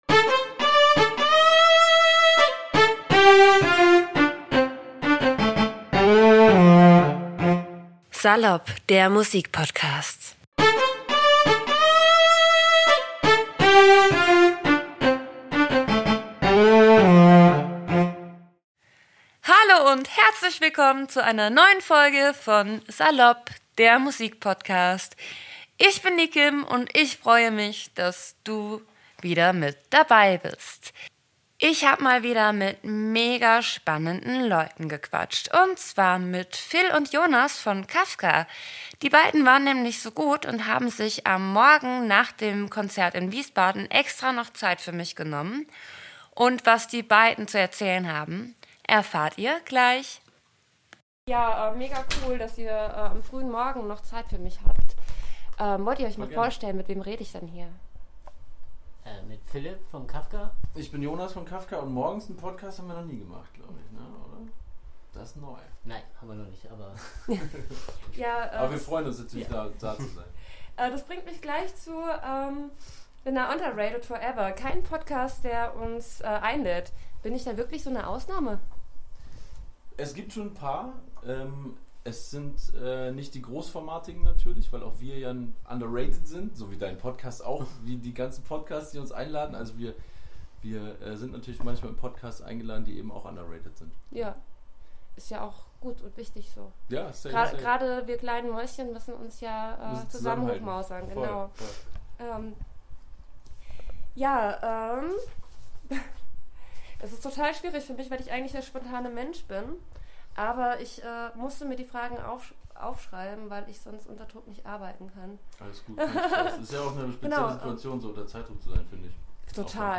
kafvka-im-interview-das-ist-schon-ein-bisschen-links-was-ich-sag-mmp.mp3